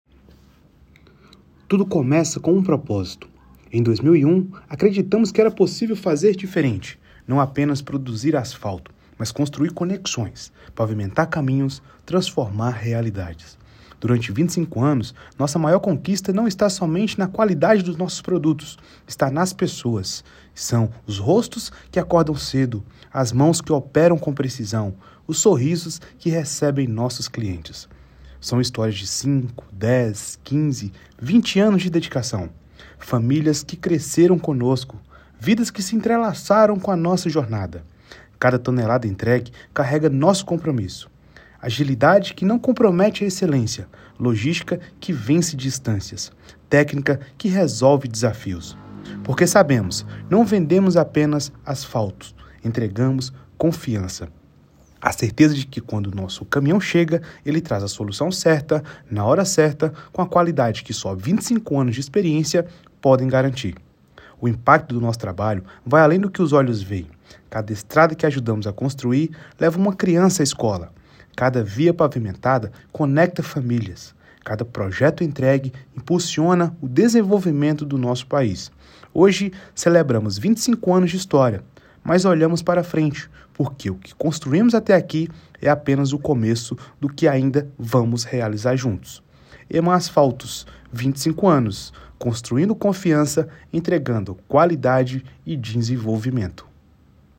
O objetivo é fazer um vídeo manifesto dos 25 anos. Então a voz precisa ser padrão, mas acolhedora.